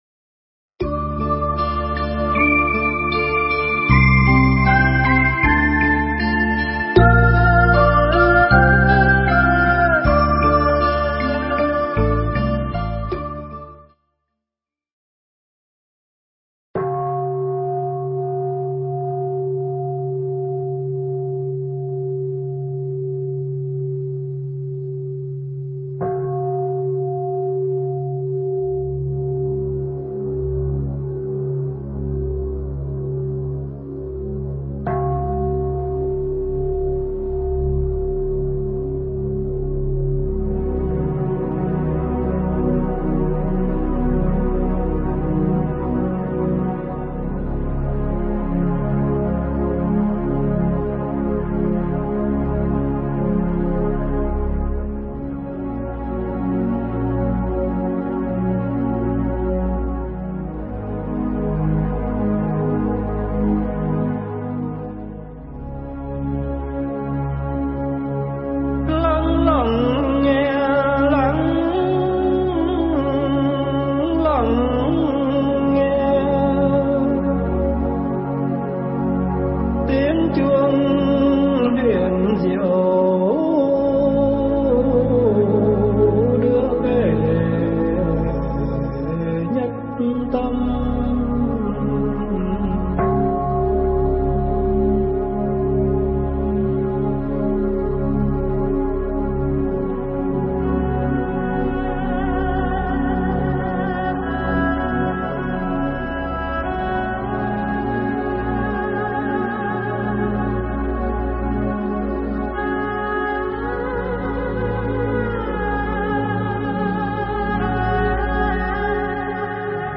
Nghe Mp3 thuyết pháp An Lạc Đến Từ Đâu Phần 1